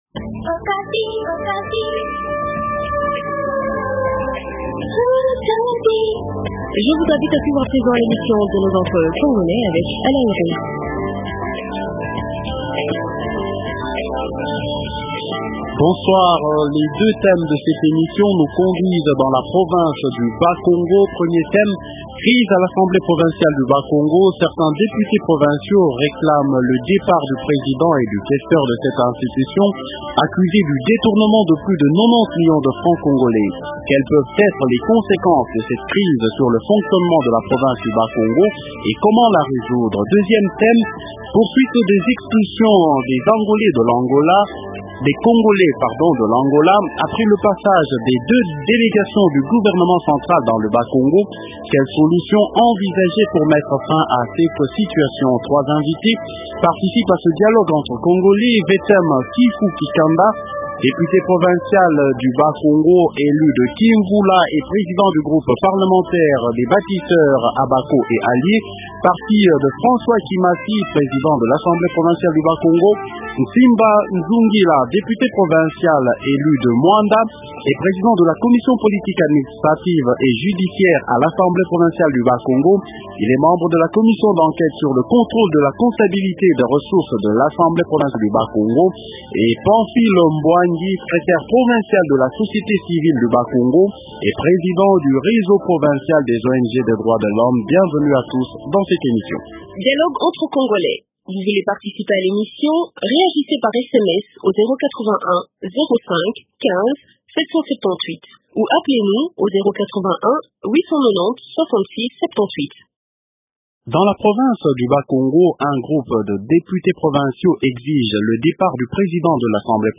Deux thèmes dans l’émission Dialogue entre congolais de ce soir :